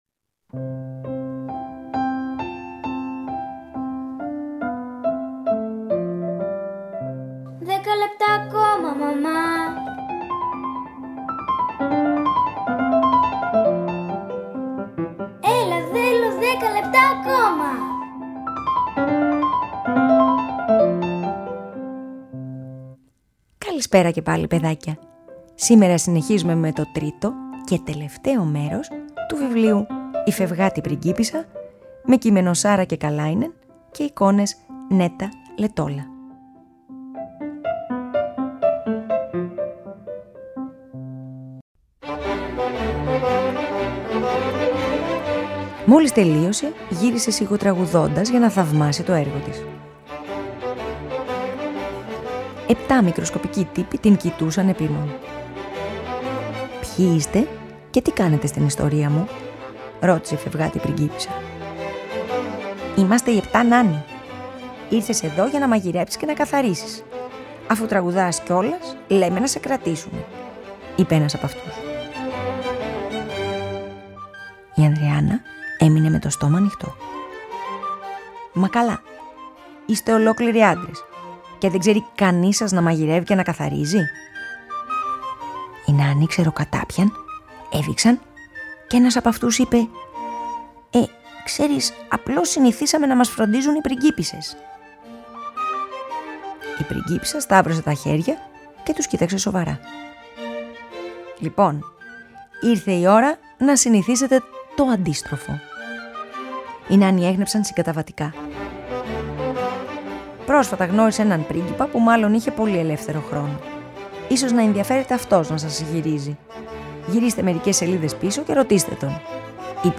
Γ΄ μέρος “Η φευγάτη πριγκίπισσα” της Saara Kekalainen εκδόσεις Άμμος Ένα βιβλίο για μία αντισυμβατική πριγκίπισσα που αγαπά τις περιπέτειες. Αφήγηση-Μουσικές επιλογές